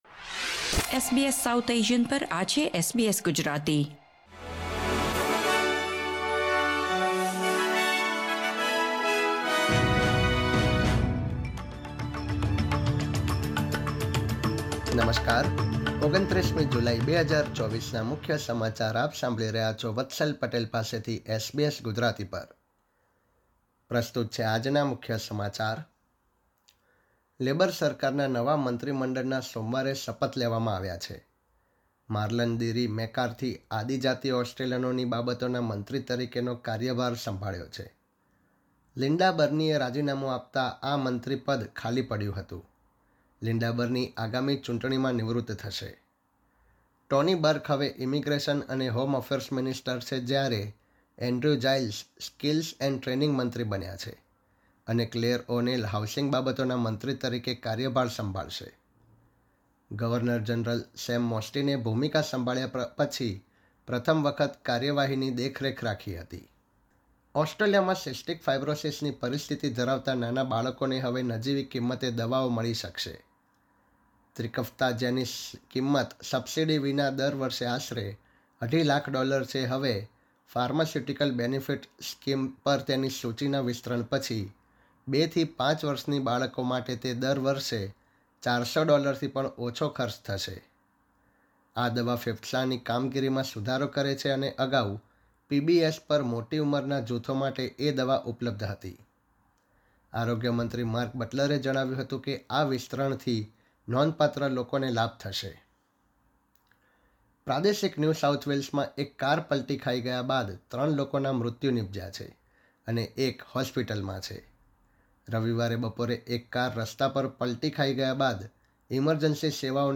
SBS Gujarati News Bulletin 29 July 2024